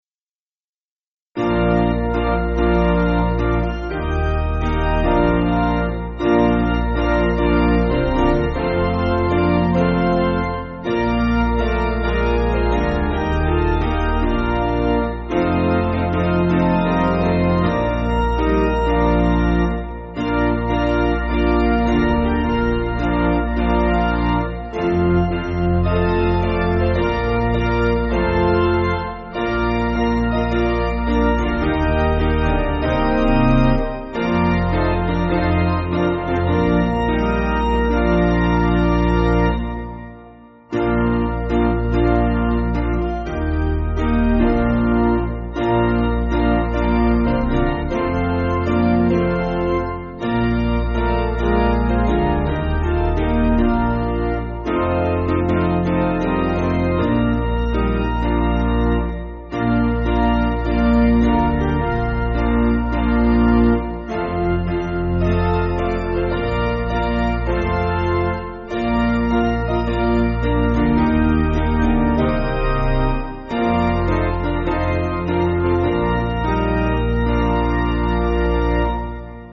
Basic Piano & Organ
(CM)   5/Bb